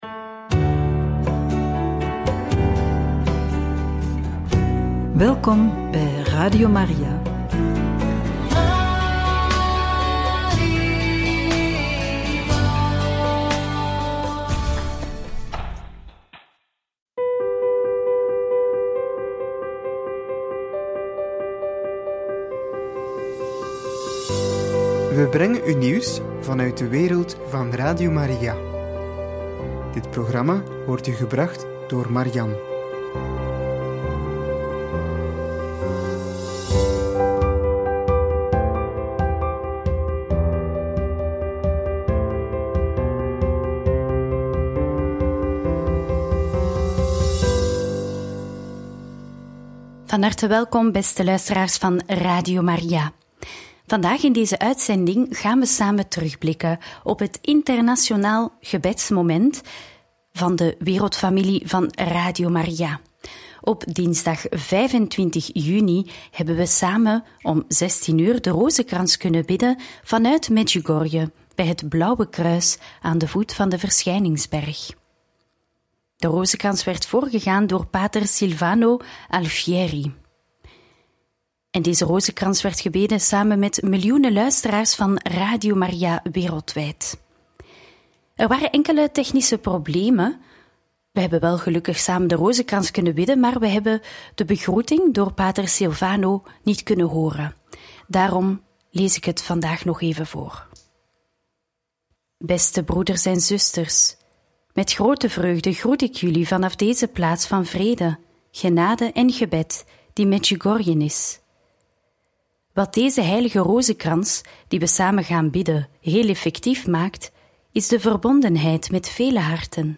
Terugblik op de live rozenkrans met alle Radio Maria’s wereldwijd vanuit Medjugorje! – Radio Maria